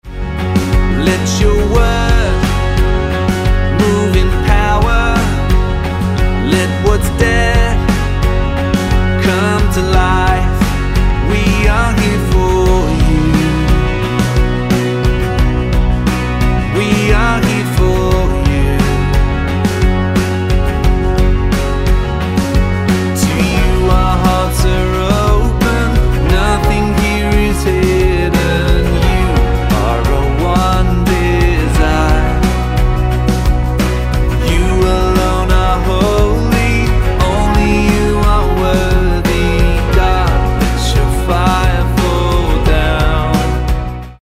F#